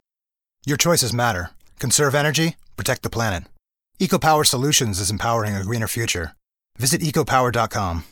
Male
My natural speaking voice is a medium pitch with a friendly, engaging tone, but I can pitch upwards and downwards, including doing "unclean" style voices for monsters and such.
Corporate
Words that describe my voice are Warm, natural, enaging.